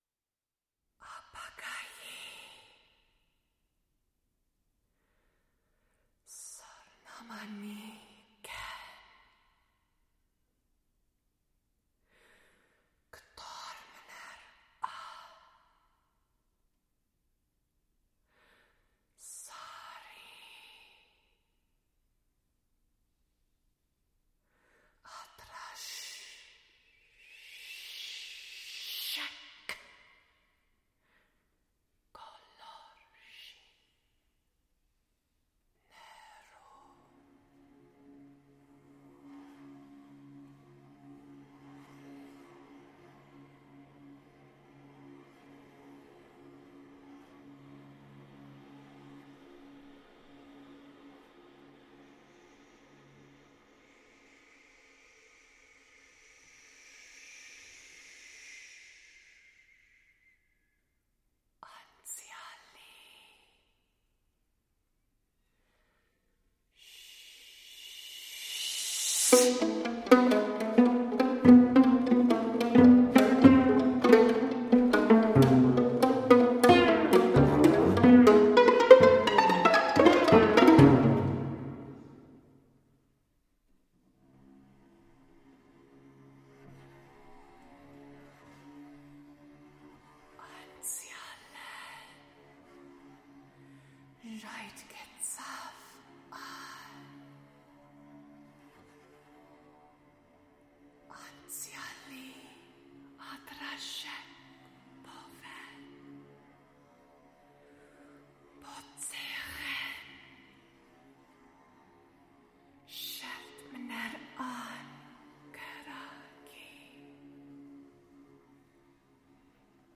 mezzo-soprano
viola